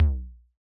Index of /musicradar/retro-drum-machine-samples/Drums Hits/Raw
RDM_Raw_SY1-Tom03.wav